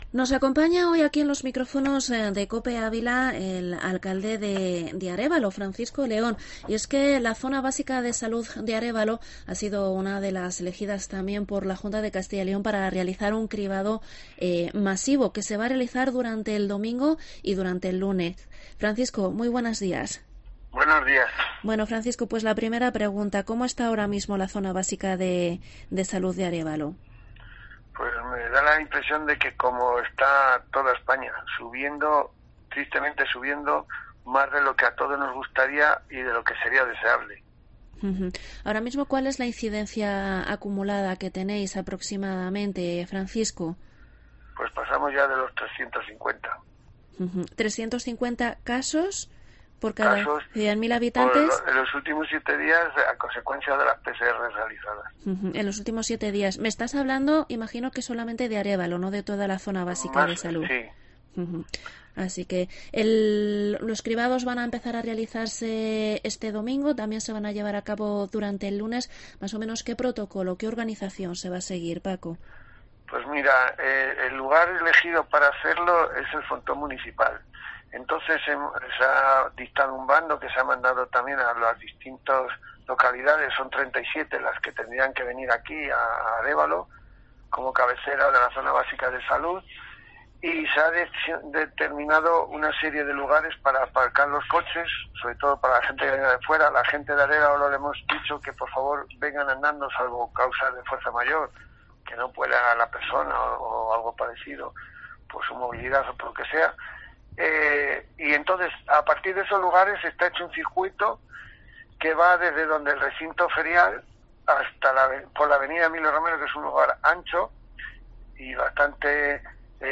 Entrevista al alcalde de Arévalo, Franciso León sobre los cribados masivos en la zona